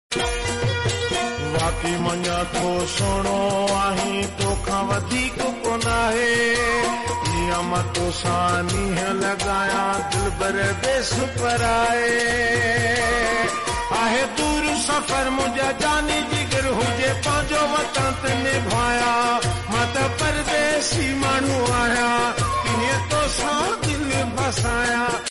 sindhi song